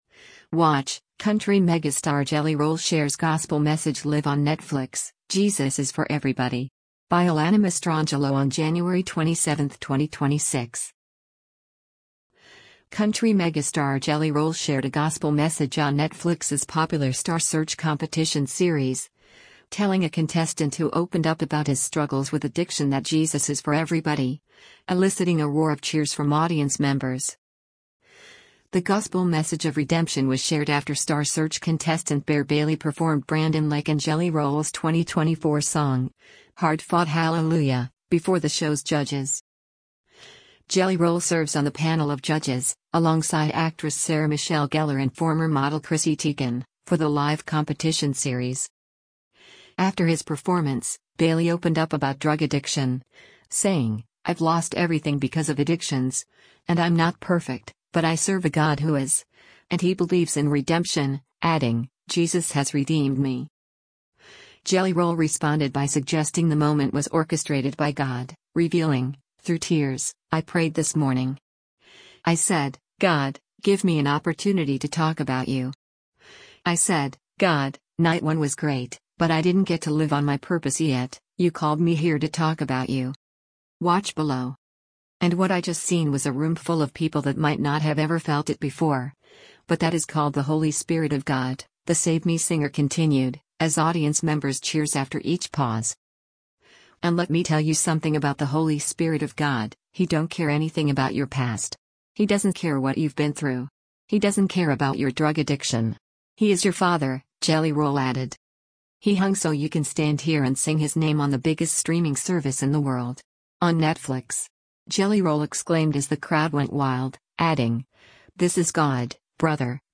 Country megastar Jelly Roll shared a Gospel message on Netflix’s popular Star Search competition series, telling a contestant who opened up about his struggles with addiction that “Jesus is for everybody,” eliciting a roar of cheers from audience members.